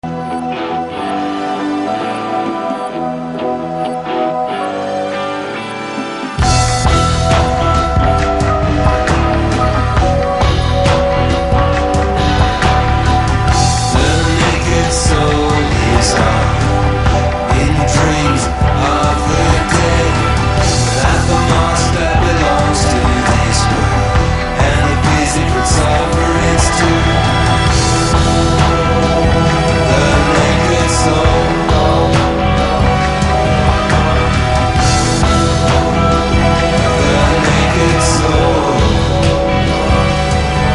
Recorded at La Maison (Bleu) and Hypnotech studios.